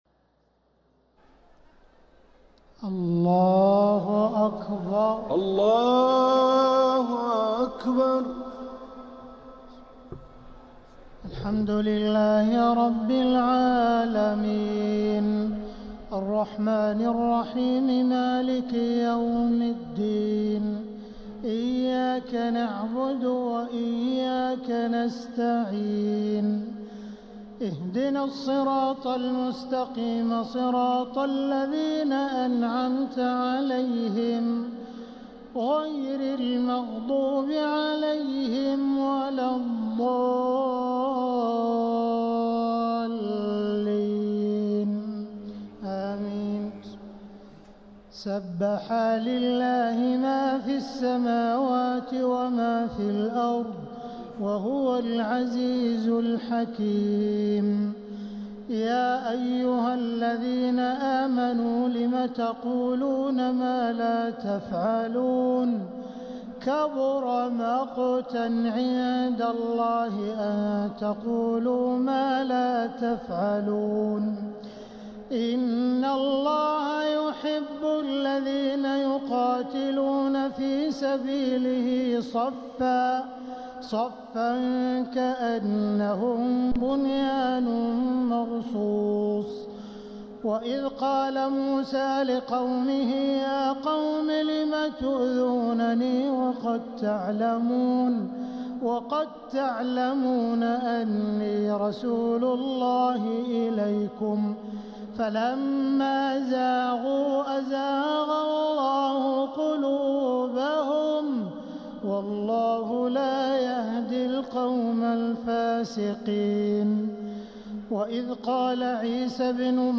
تهجد ليلة 27 رمضان 1446هـ سورتي الصف و الجمعة | Tahajjud 27th niqht Ramadan 1446H Surah As-Saff and Al-Jumu'a > تراويح الحرم المكي عام 1446 🕋 > التراويح - تلاوات الحرمين